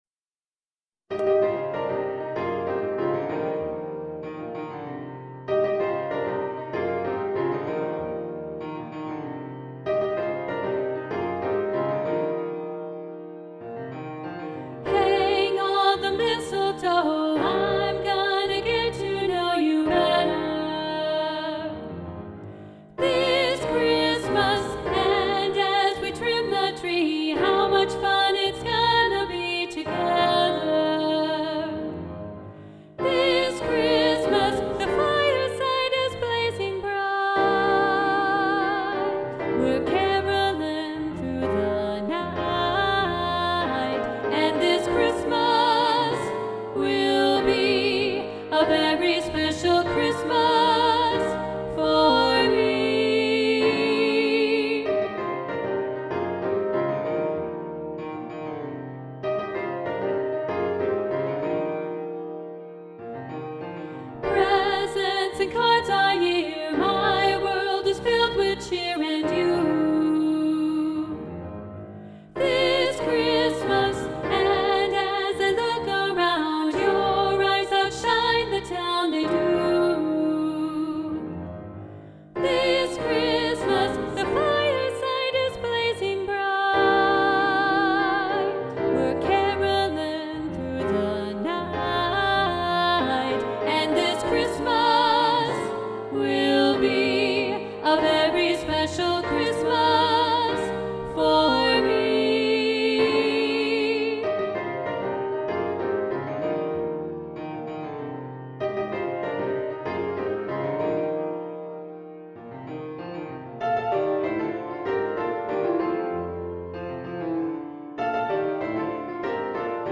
This Christmas SSA – Soprano 2 Muted – arr. Roger Emerson